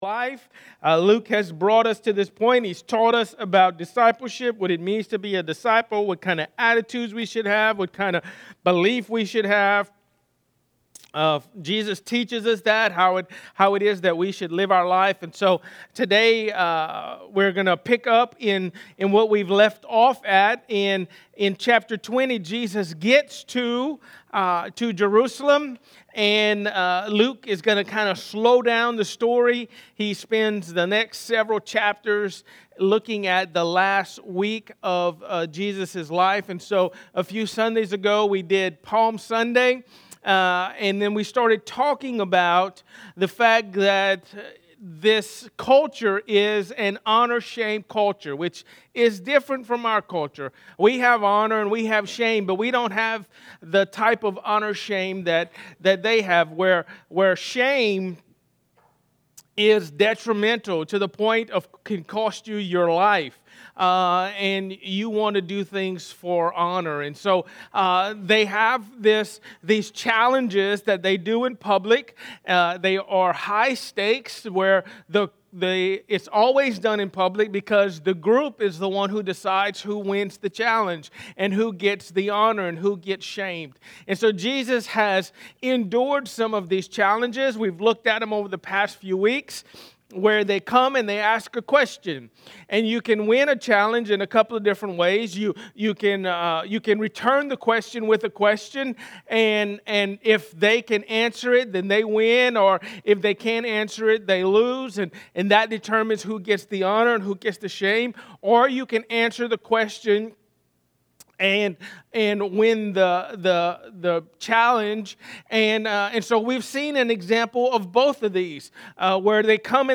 Message: “Resurrection”